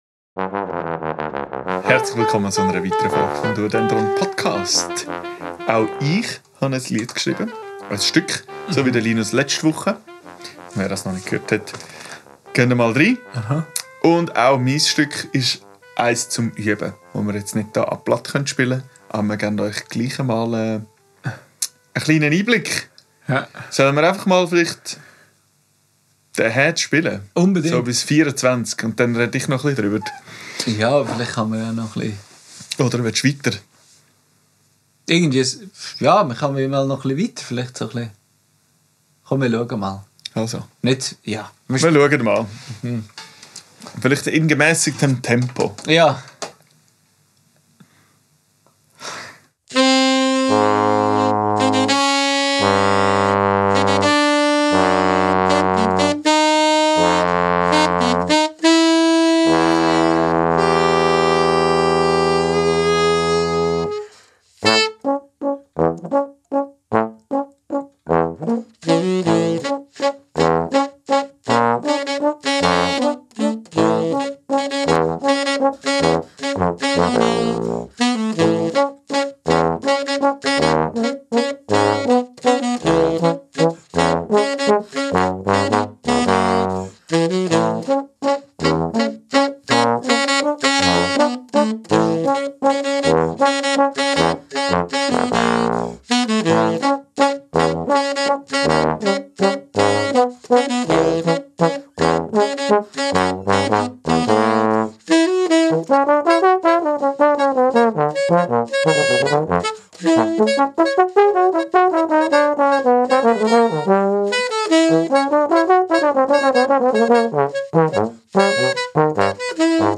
Aufgenommen am 25.03.2025 im Atelier